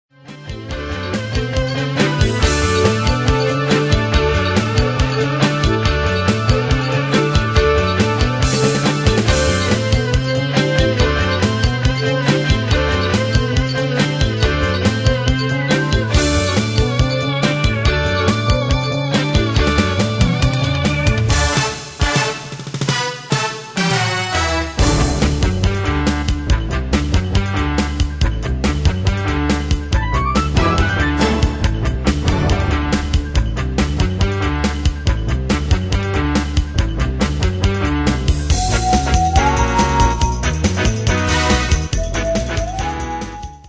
Demos Midi Audio
Style: Dance Année/Year: 1994 Tempo: 143 Durée/Time: 2.19
Pro Backing Tracks